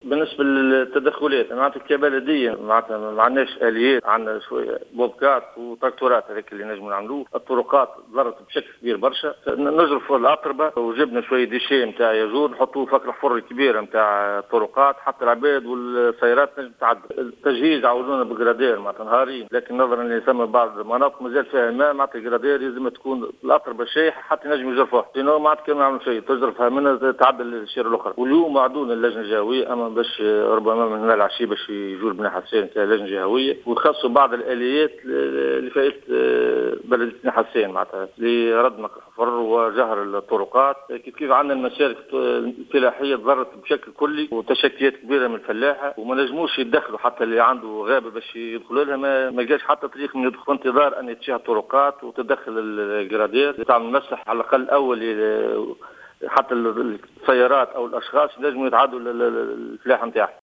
أكد معتمد بني حسّان عبد المجيد الغرسلي في تصريح للجوهرة "اف ام" اليوم الإثنين 3 أكتوبر 2016 أن الامكانيات المتوفرة حاليا لا تسمح بتدخلات كبيرة على مستوى الطرقات والمسالك المتضررة من الفيضانات الأخيرة التي شهدتها الجهة.